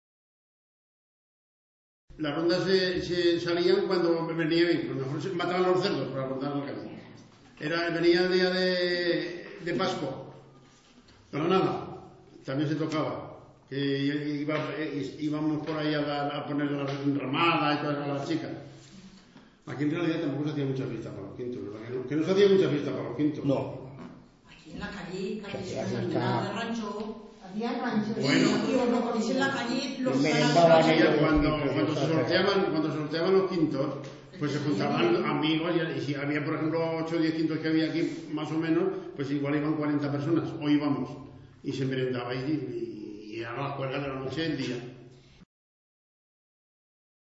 III. CONVERSACIONES
y grupo de informantes